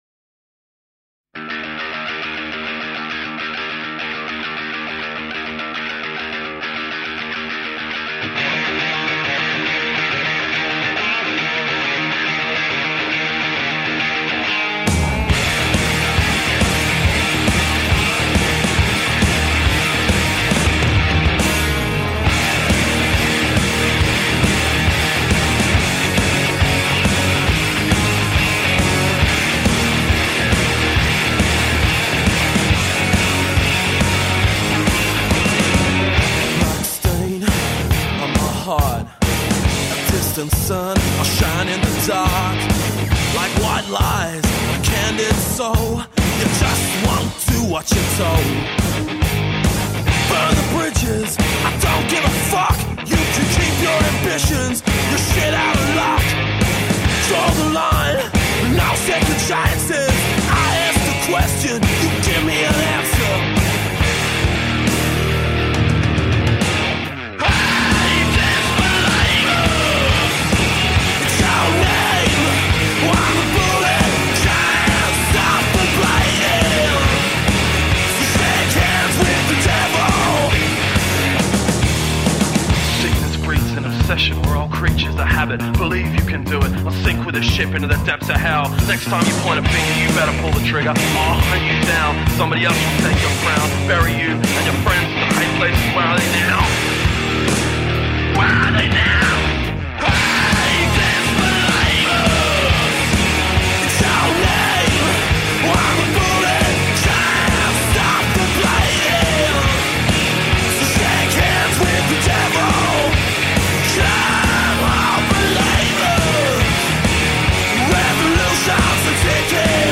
Rock and roll from the heart.
Tagged as: Hard Rock, Punk